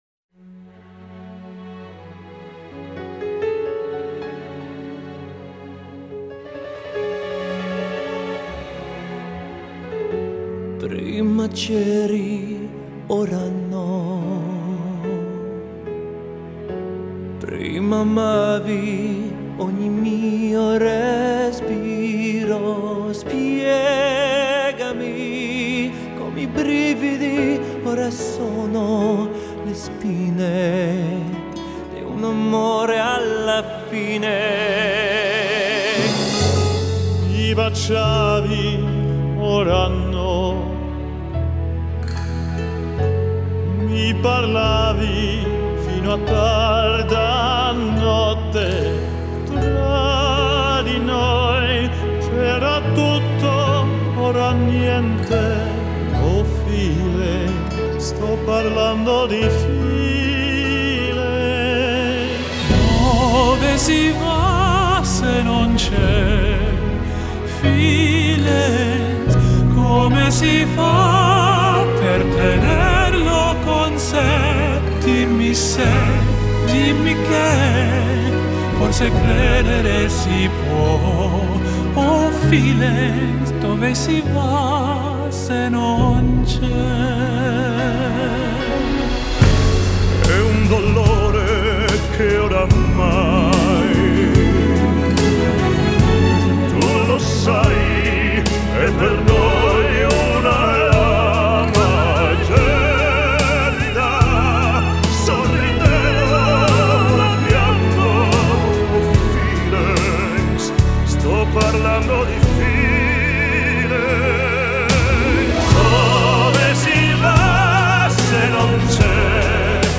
(POPERA 揉合流行歌曲唱法與古典歌劇聲樂唱法演唱流行、古典、歌劇等類型歌曲的歌唱新流派)